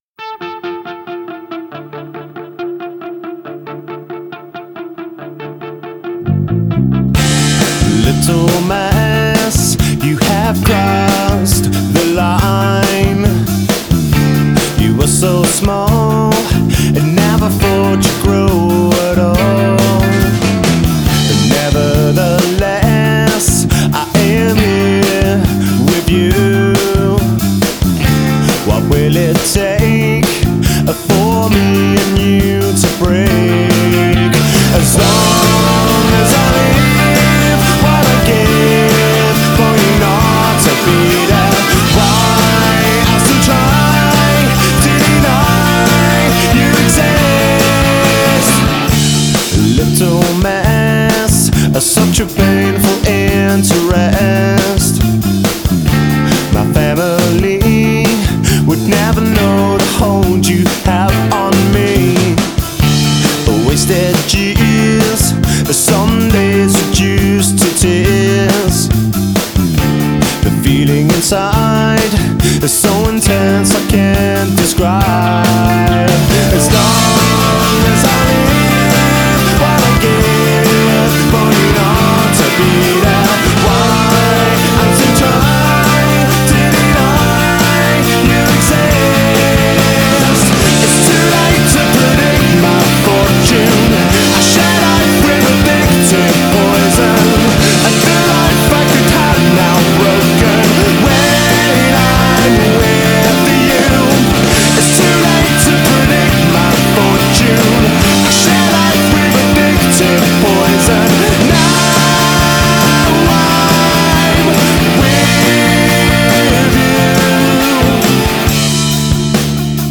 Genre: Pop / Rock